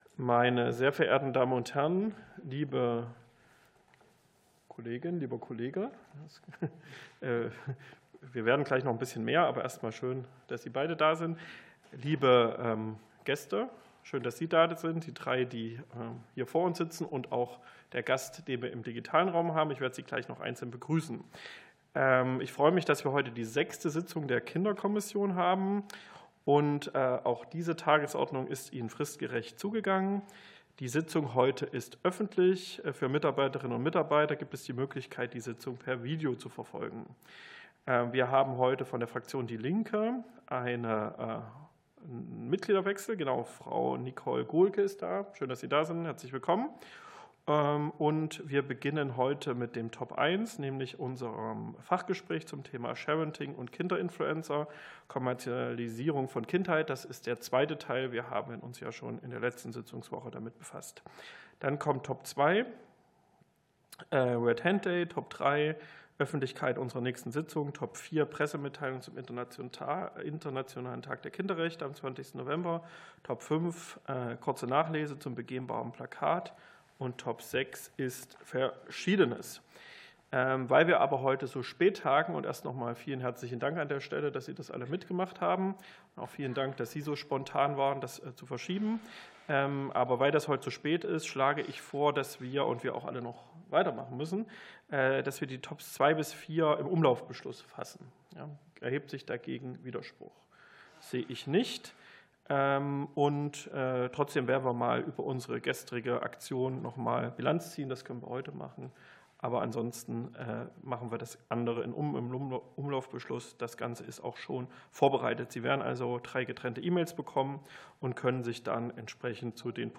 Fachgespräch der Kinderkommission (Teil 2)